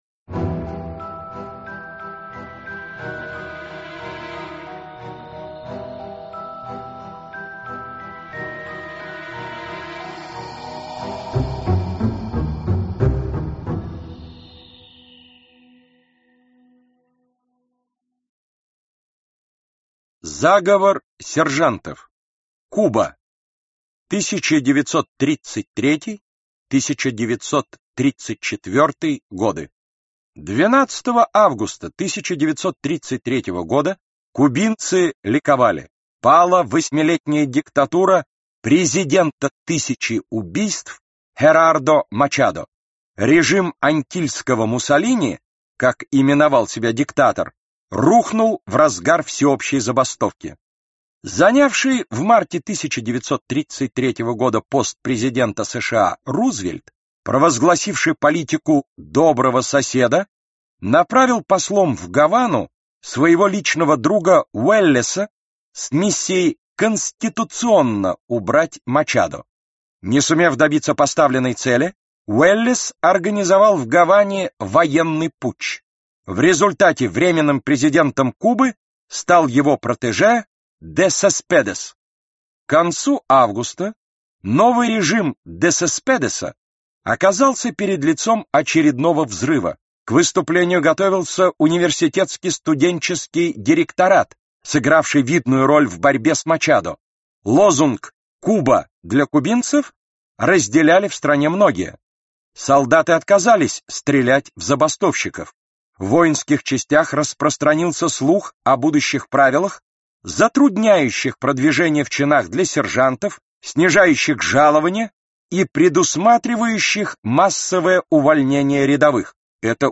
Аудиокнига Великие покушения часть 2 | Библиотека аудиокниг